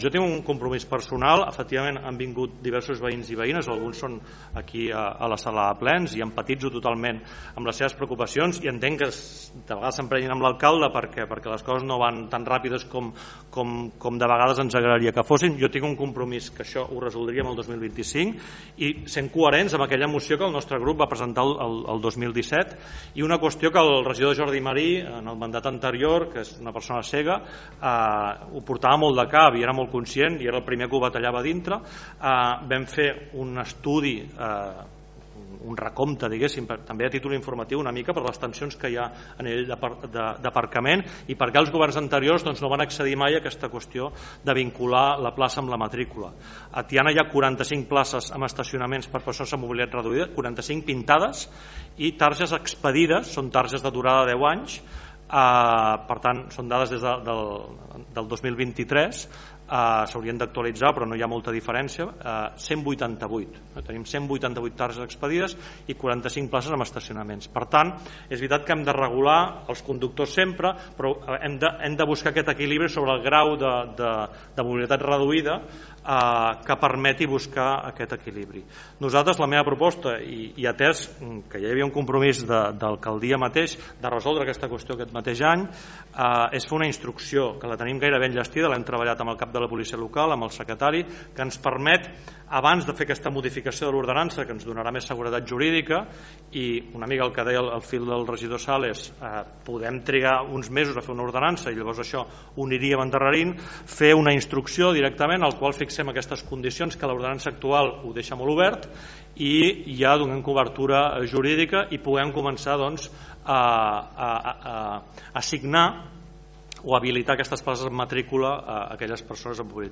L’alcalde de Tiana, Isaac Salvatierra, ja va avançar en ple del mes de febrer que faria aquesta instrucció com a règim transitori fins a l’aprovació de la modificació de l’Ordenança municipal de circulació i seguretat viària de l’Ajuntament de Tiana que l’adapti a la normativa estatal i autonòmica vigent: